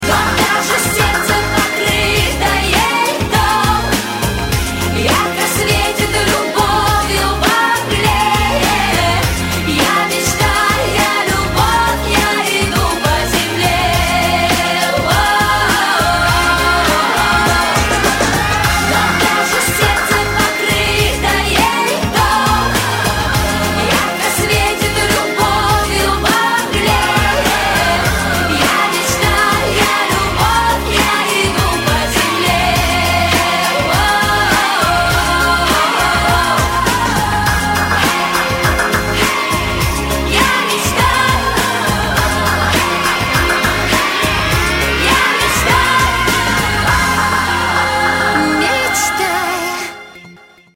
• Качество: 160, Stereo
поп
женский вокал
восточные